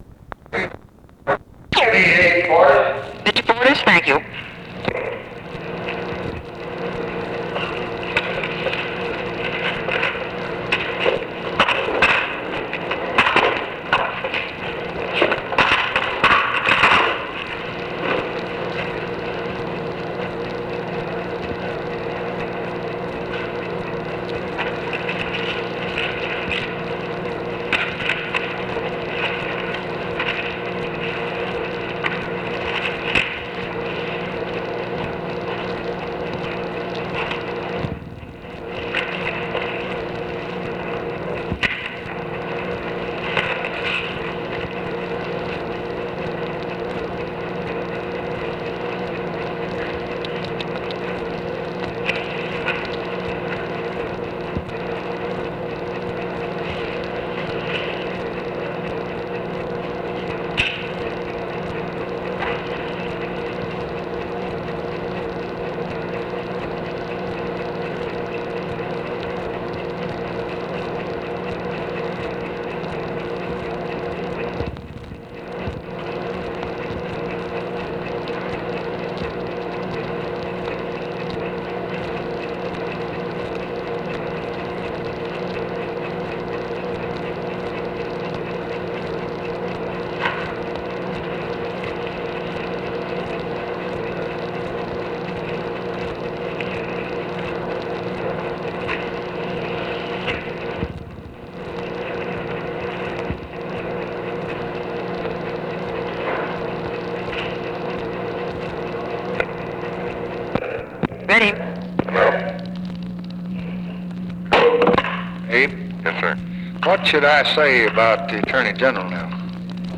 Conversation with ABE FORTAS and TELEPHONE OPERATOR, September 3, 1964
Secret White House Tapes